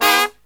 FALL HIT04-R.wav